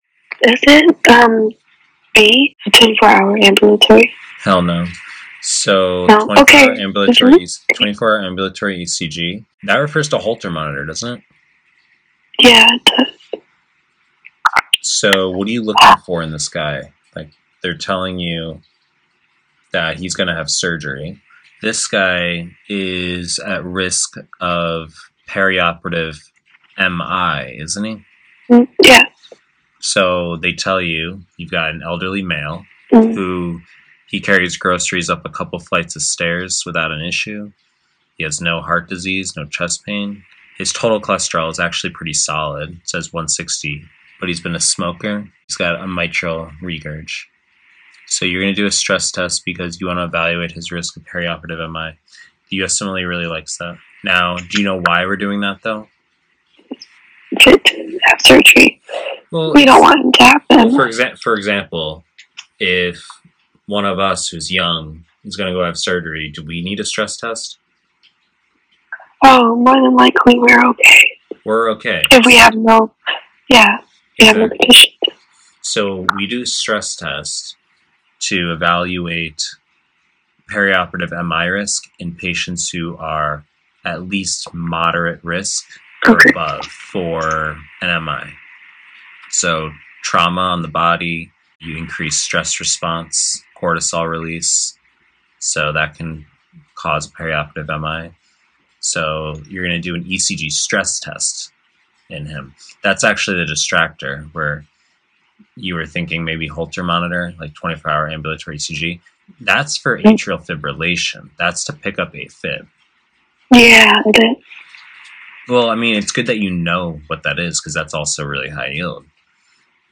HY lecture notes (double-posting this lecture as Internal medicine #14 because it’s exceedingly HY for both shelf exams):